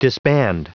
Prononciation du mot disband en anglais (fichier audio)
Prononciation du mot : disband